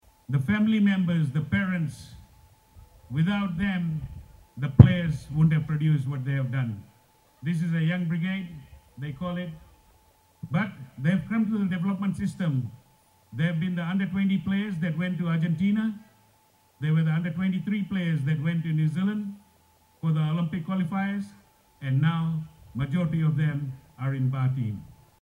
The Fiji FA President also told fans that they are hoping to have the IDC played at Govind Park if all goes well and the stadium is ready.